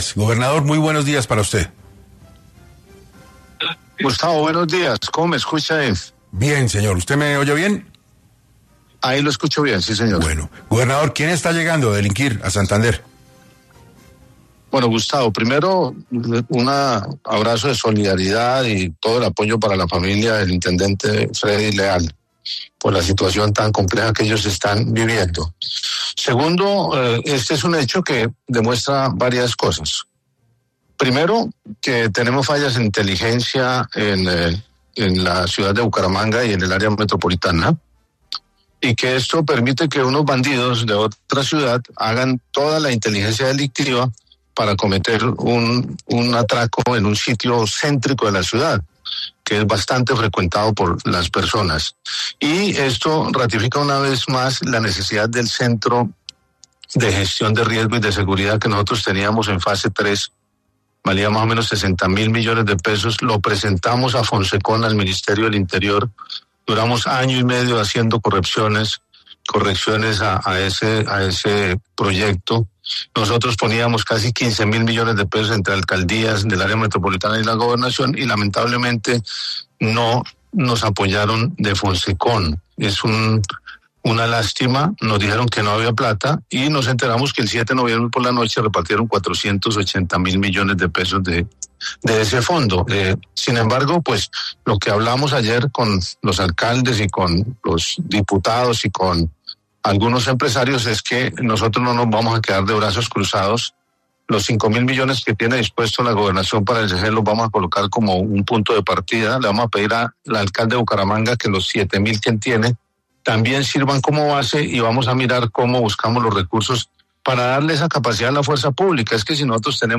Frente a este tema, Juvenal Díaz, gobernador de Santander, se pronunció en entrevista para 6AM, donde explicó las causas que derivaron en el intento de robo ocurrido en la joyería: “Este hecho demuestra varias cosas: tenemos fallas de inteligencia en Bucaramanga y su área metropolitana.